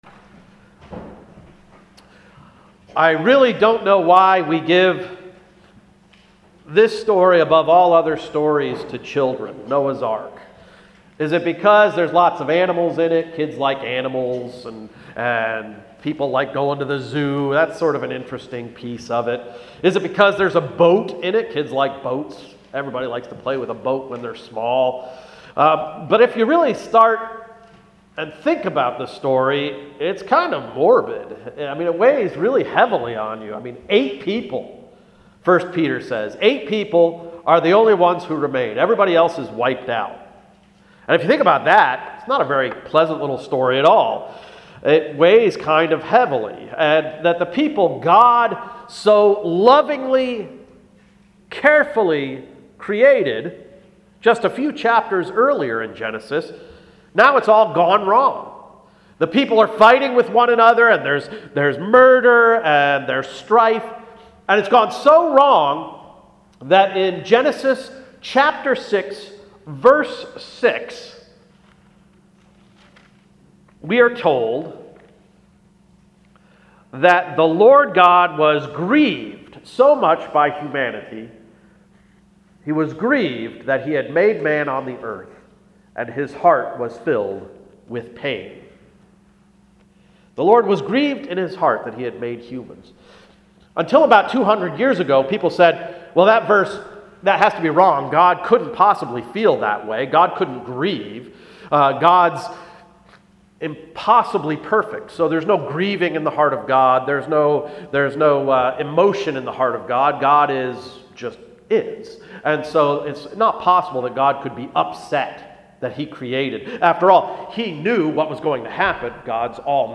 January 31, 2016 Sermon–Year of the Bible–“A God who Promises”